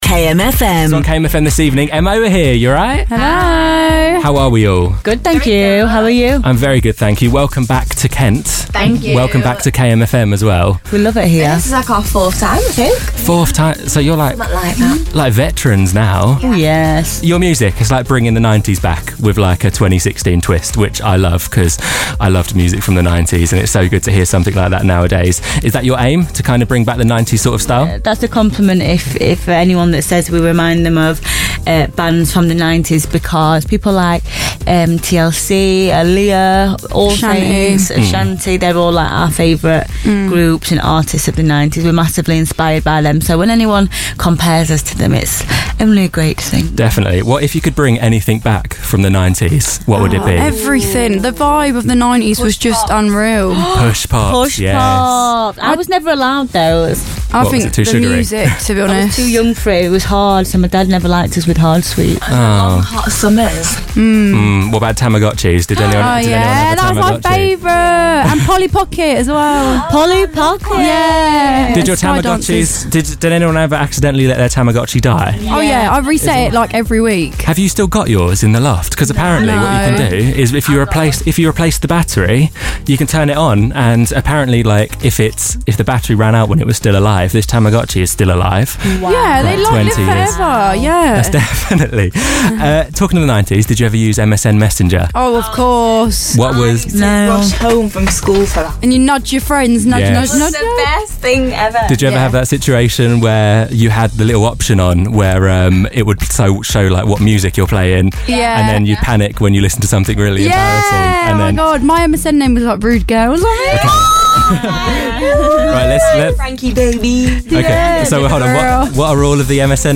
M.O came down to our Kent studios to chat all about the new single, although we went a little off topic.